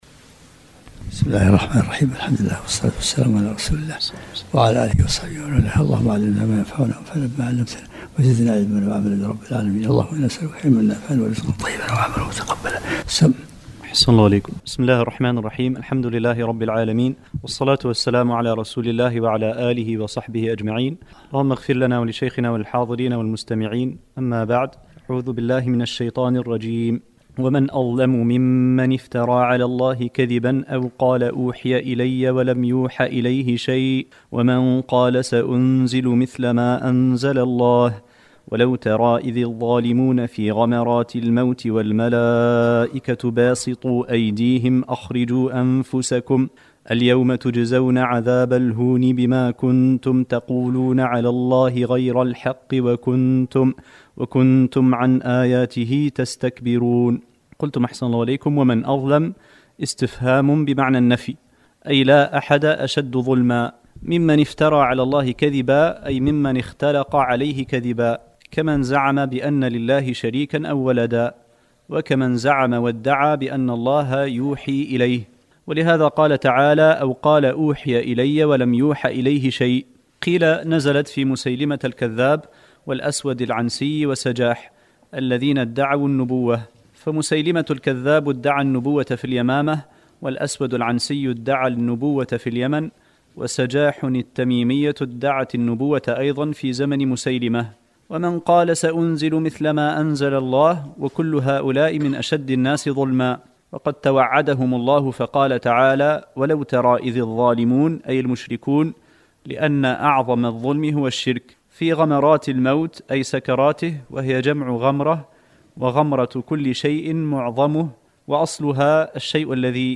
الدرس العشرون من سورة الانعام